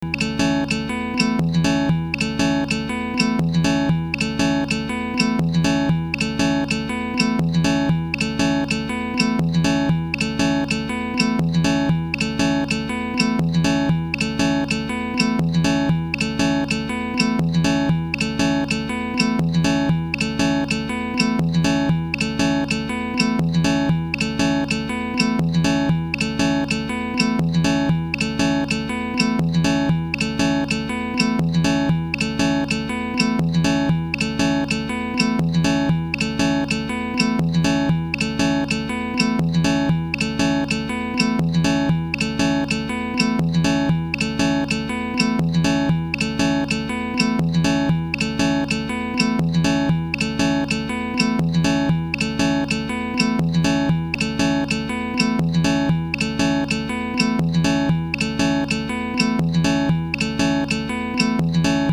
ダンス、エレクトロニカ、テクノ、ヒップホップ